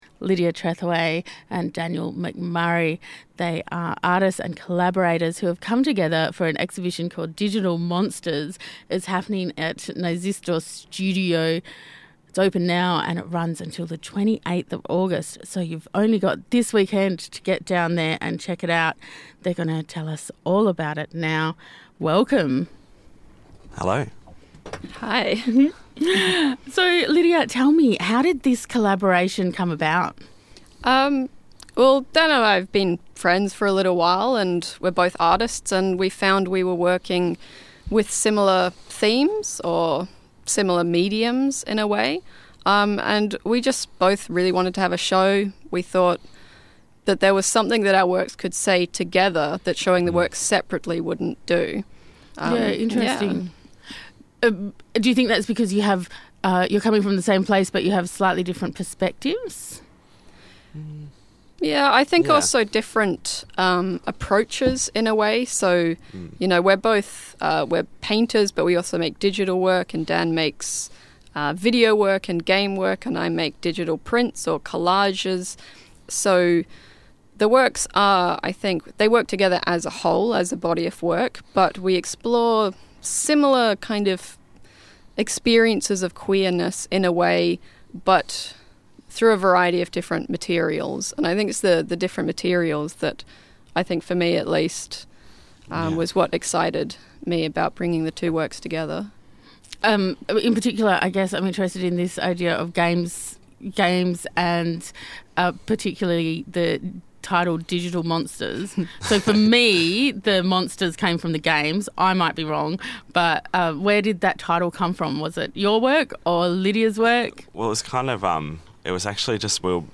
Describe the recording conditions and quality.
in the studio for Artbeat to chat about how the show came about and what audiences can expect.